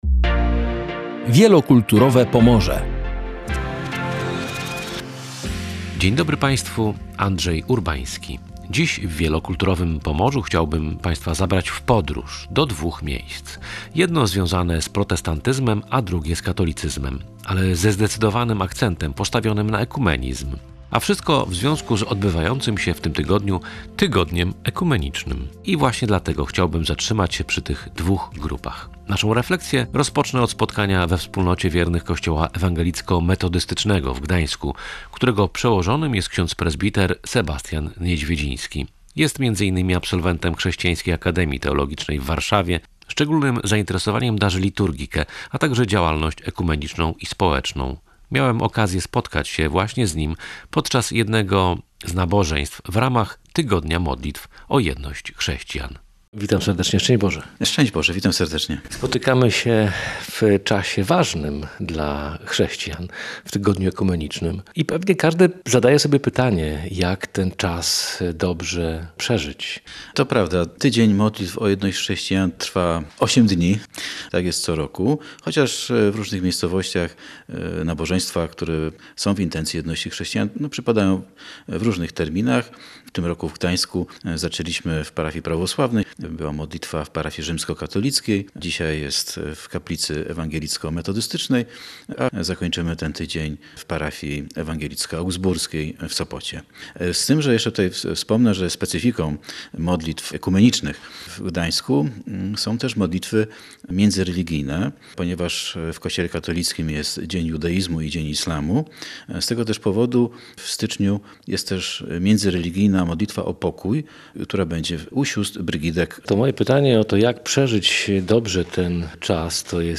Naszą refleksję rozpoczęliśmy od spotkania we wspólnocie wiernych kościoła ewangelicko-metodystycznego w Gdańsku
Od wspólnoty protestanckiej przeszliśmy do Centrum Ekumenicznego, które funkcjonuje od wielu lat w Gdańsku-Oliwie.